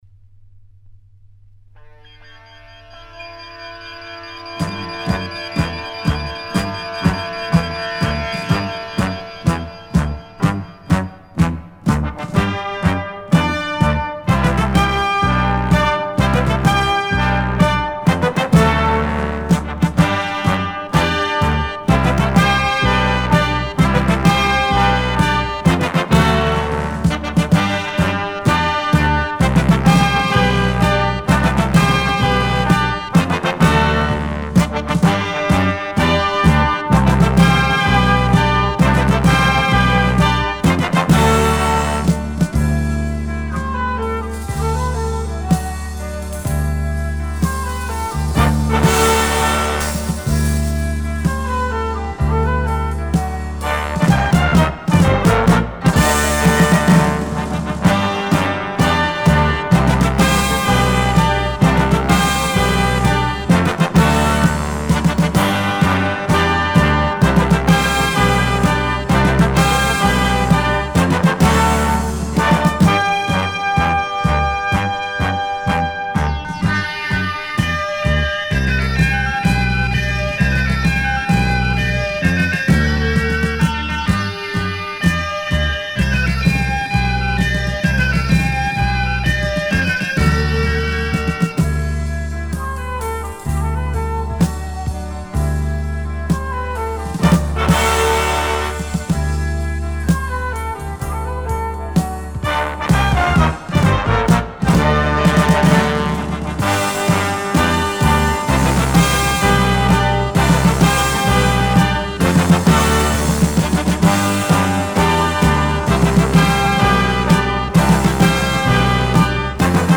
Лучший мировой инструментал